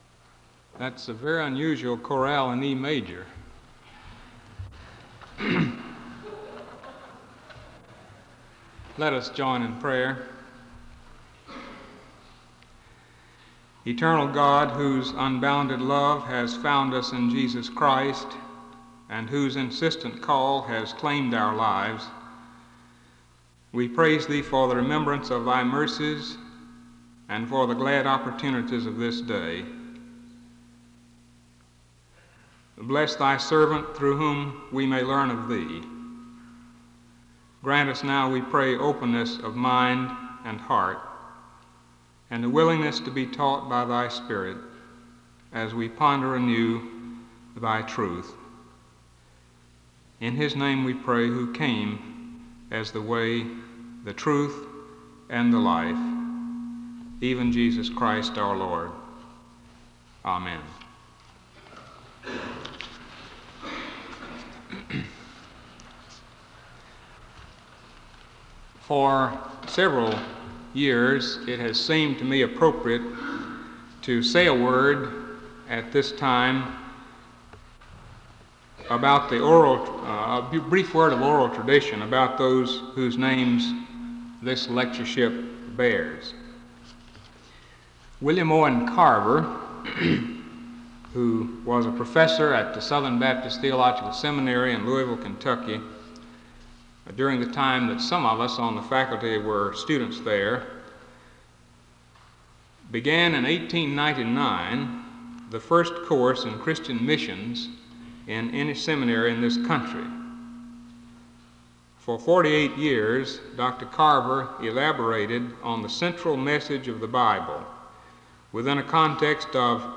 SEBTS Carver-Barnes Lecture - Phyllis Trible March 28, 1979